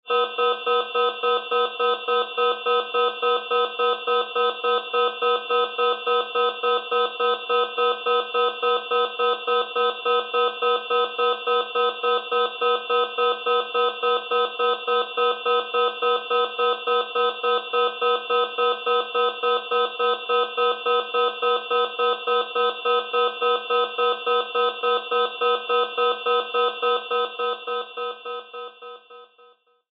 Звуки сигналов тревоги
Тревожная сигнализация на производственном объекте